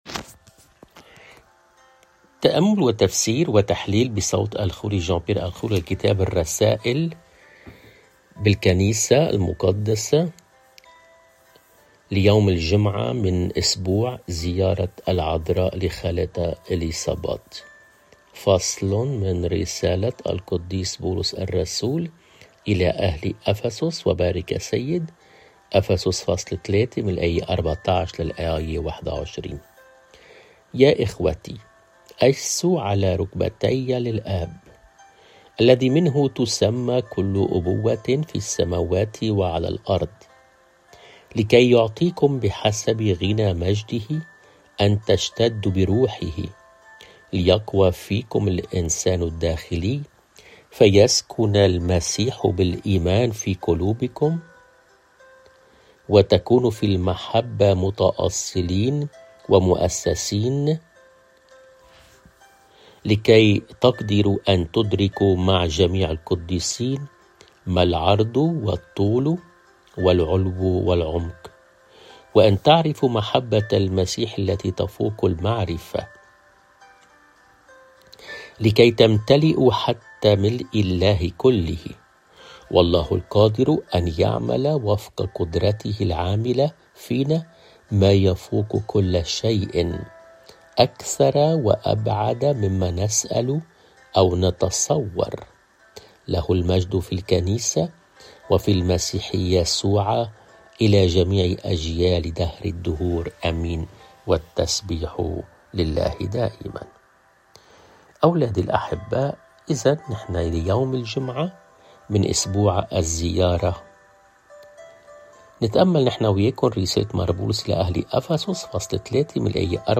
الرسالة والإنجيل